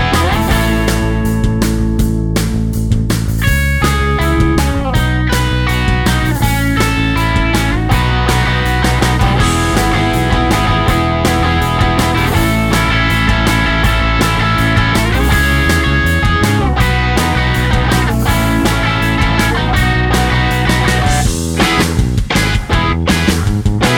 no harmonica Indie / Alternative 3:21 Buy £1.50